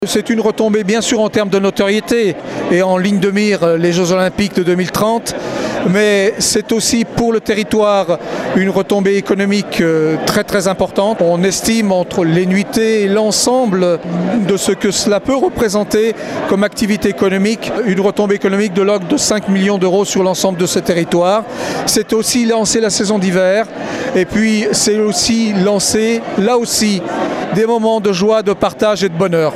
André Perrillat-Amédé est le président du comité d’organisation et maire du Grand Bornand :